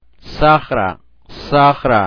Tabla I: Alfabeto Oficial sonorizado
Fricativas . s